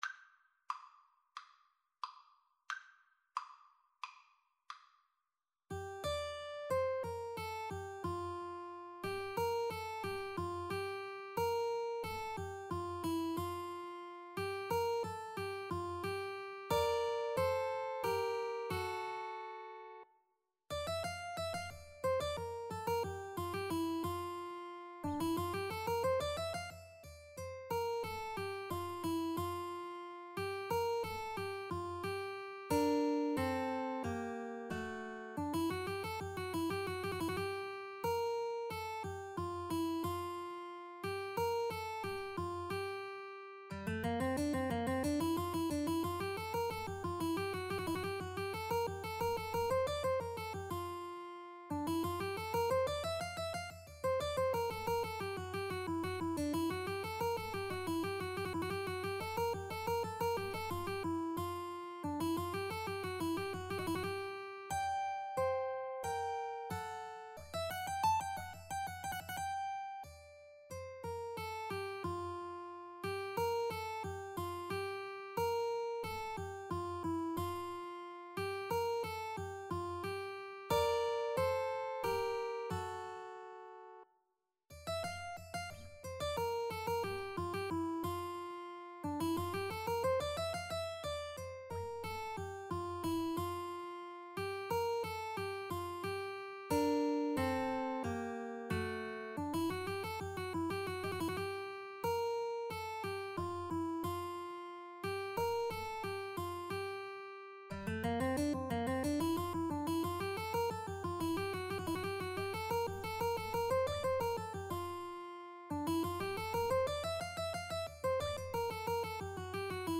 Anonymous early renaissance piece.
A minor (Sounding Pitch) (View more A minor Music for Mandolin-Guitar Duet )
Mandolin-Guitar Duet  (View more Intermediate Mandolin-Guitar Duet Music)
Classical (View more Classical Mandolin-Guitar Duet Music)